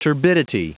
Prononciation du mot turbidity en anglais (fichier audio)
Prononciation du mot : turbidity